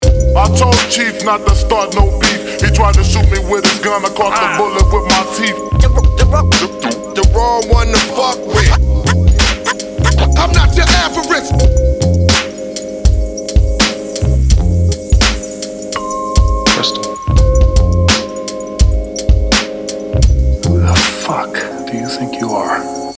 New beat